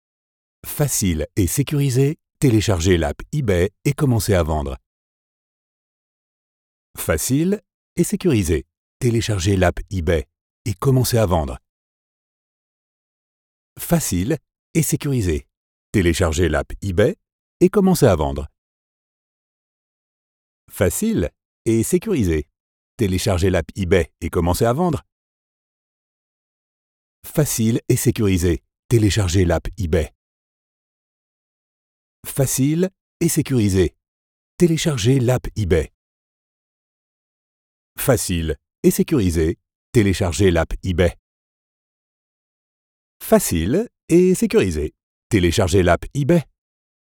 Voix off
30 - 50 ans - Basse